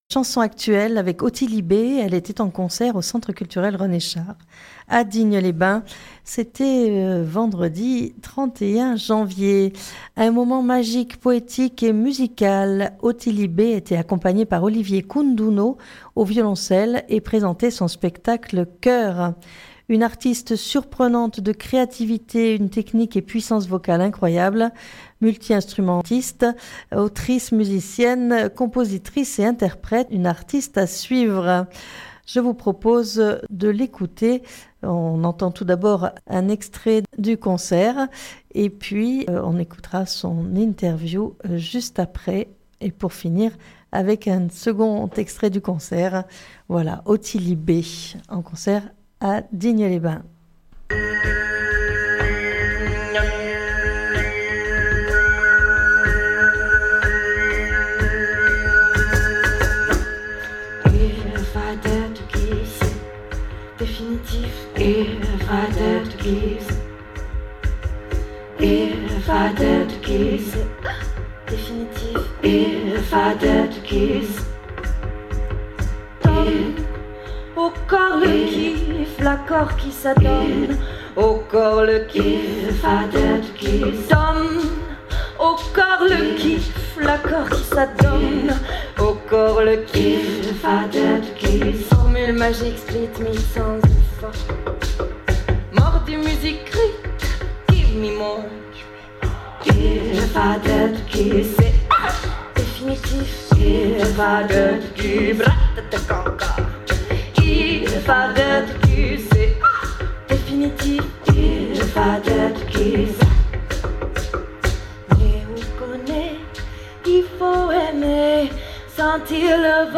Chanson actuelle
en vidéo mais aussi des extraits du spectacle Coeur